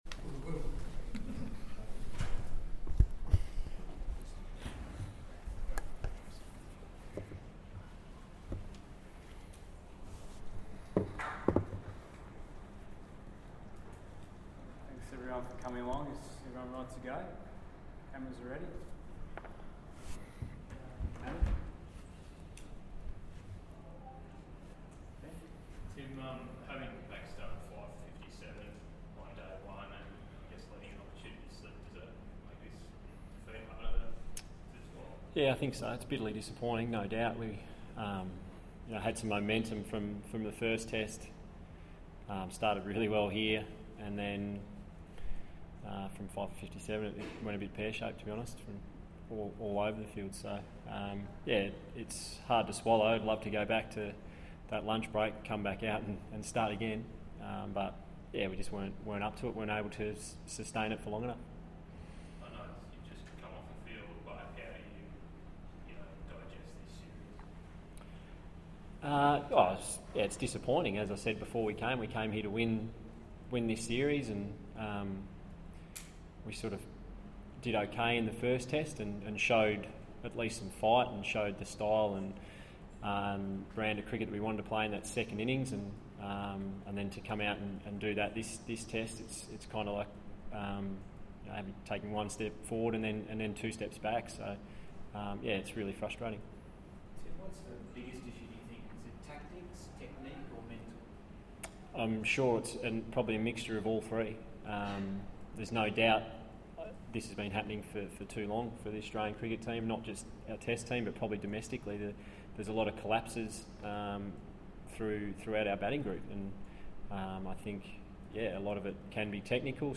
Tim Paine speaks with the media after Pakistan won the series 1-0
Australian Test captain, Tim Paine, spoke to the media after Pakistan won the series 1-0 in Abu Dhabi.